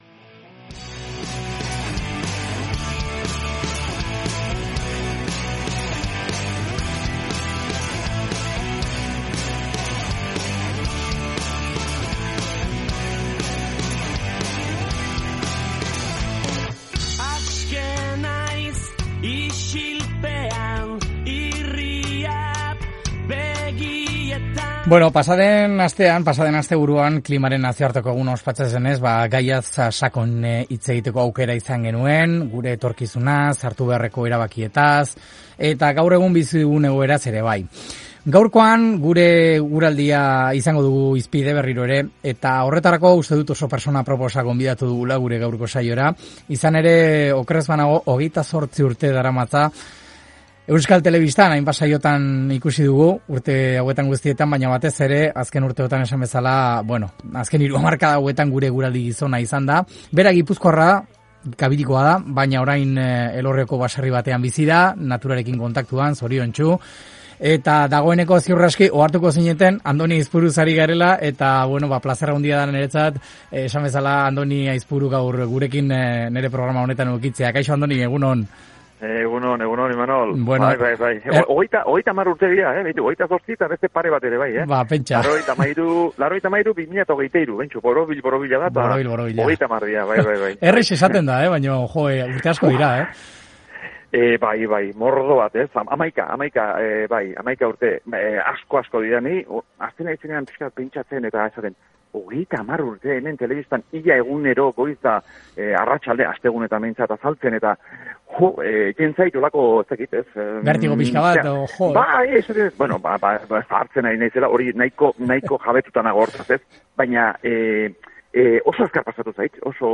Gaurkoan ere Onda Vascan gure eguraldia izan dugu hizpide, Aste Santua atejoka dugunean. Horretarako, ezin pertsona aproposagoa gonbidatu dugu asteburutako saiora.